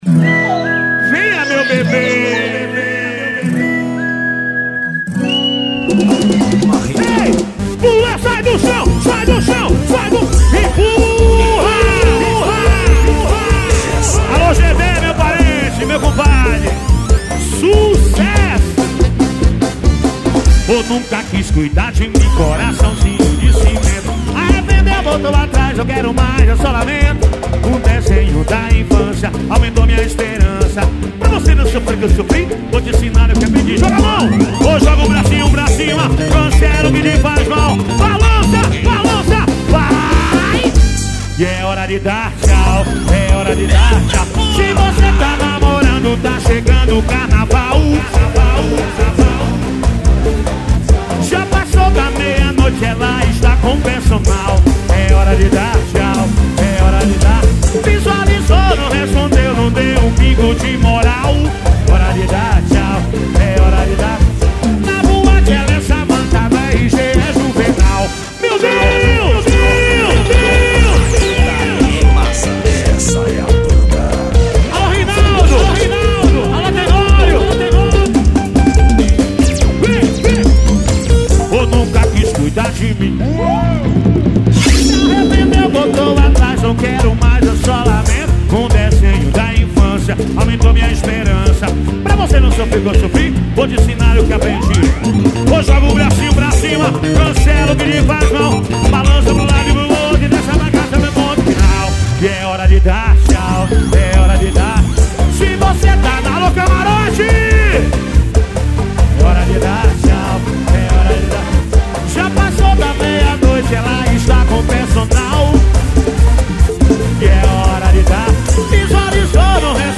é uma música